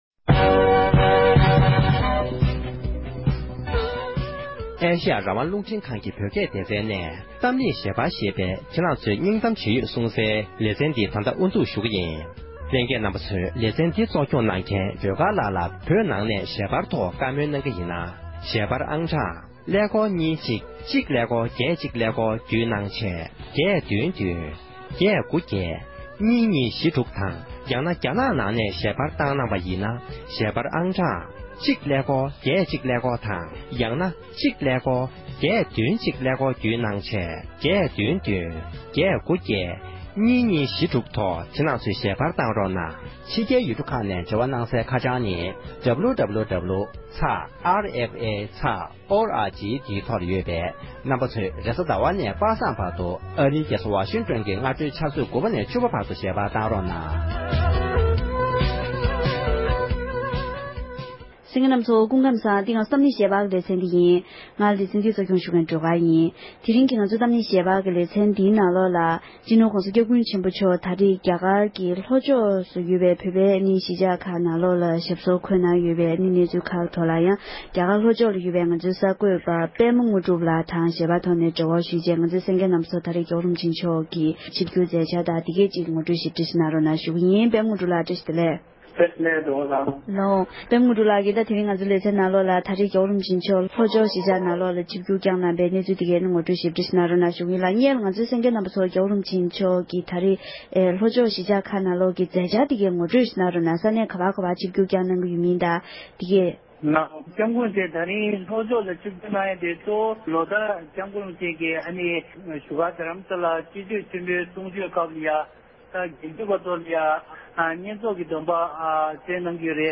༸གོང་ས་མཆོག་གི་རྒྱ་གར་ལྷོ་ཕྱོགས་ཁུལ་གྱི་མཛད་འཆར་སྐོར་གླེང་མོལ་ཞུས་པའི་ལེ་ཚན།